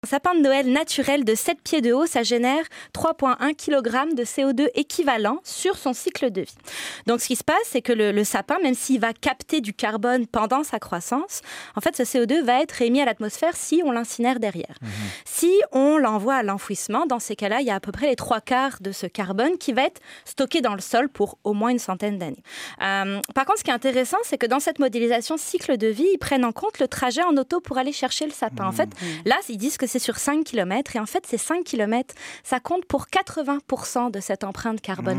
en entrevista con la radio francesa de Radio Canadá